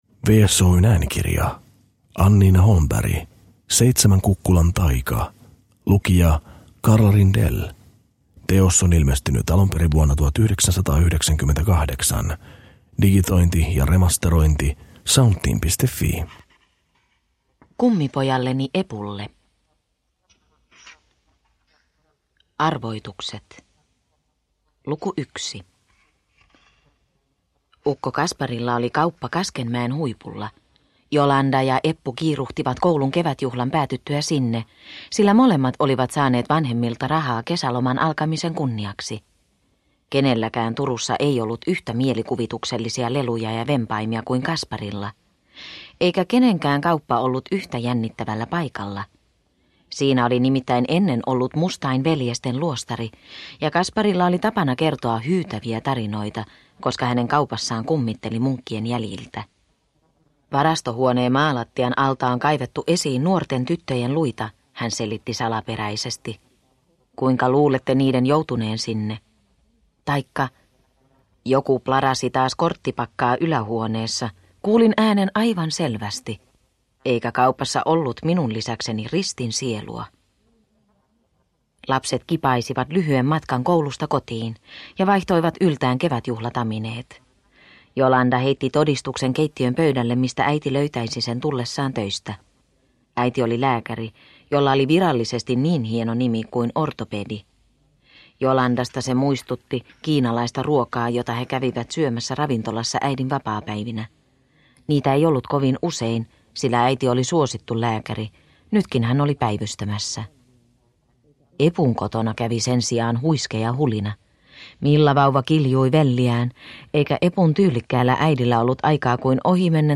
Seitsemän kukkulan taika – Ljudbok